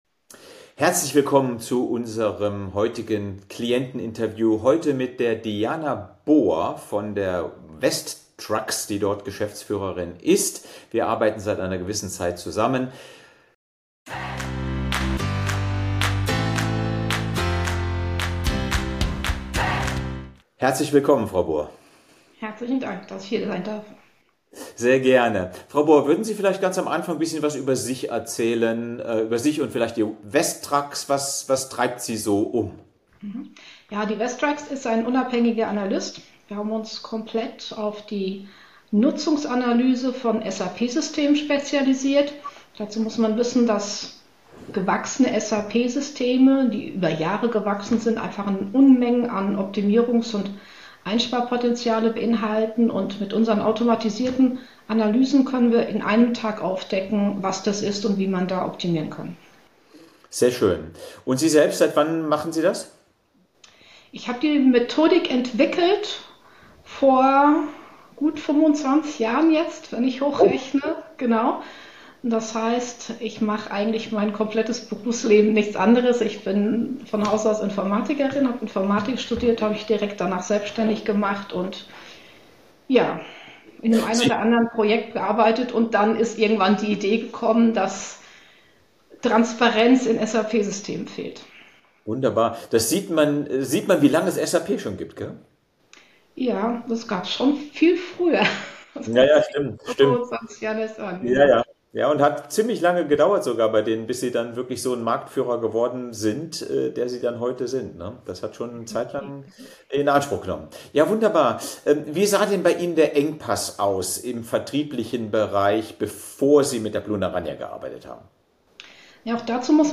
im Interview ~ Lessons To Grow - Wachstum - Führung - Erfolg Podcast